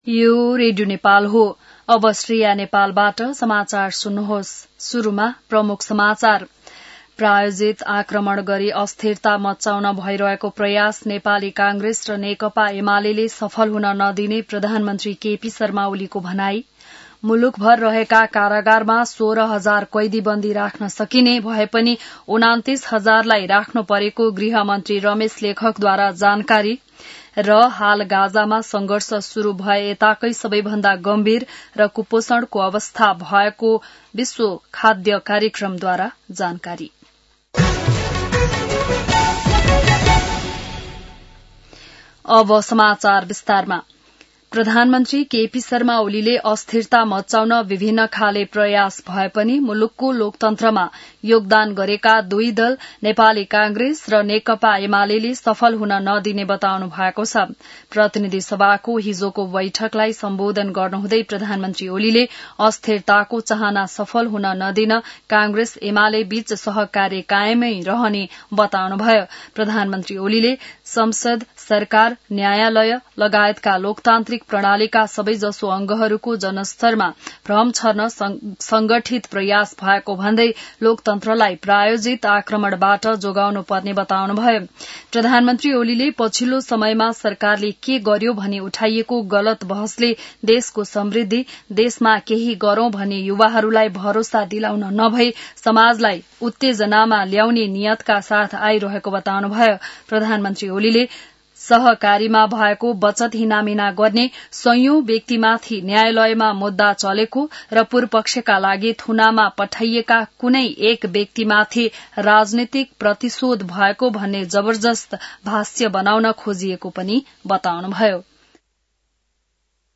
बिहान ९ बजेको नेपाली समाचार : २९ साउन , २०८२